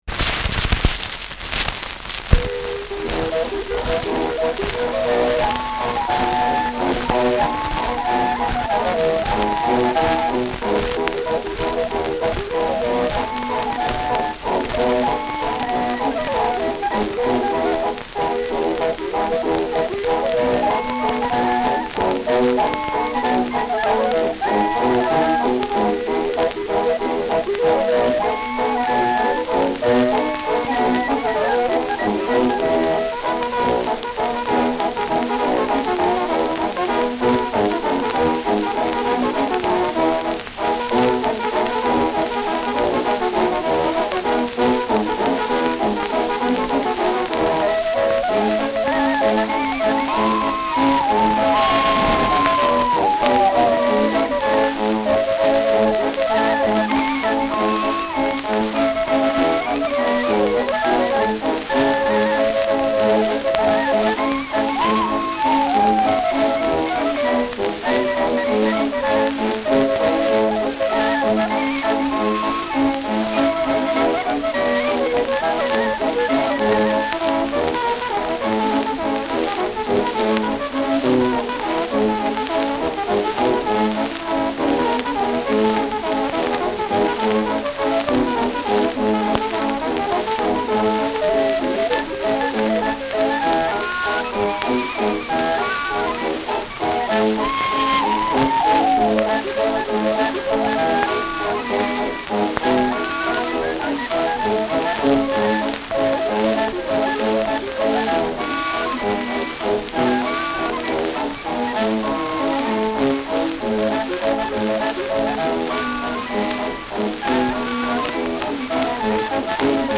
Schellackplatte
Stark abgespielt : Starkes Grundrauschen : Nadelgeräusch
[Nürnberg] (Aufnahmeort)